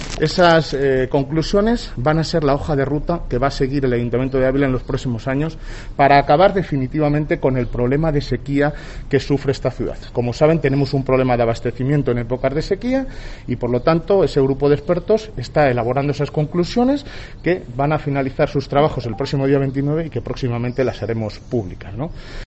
Alcalde de Ávila. Conclusiones Mesa de Expertos del Agua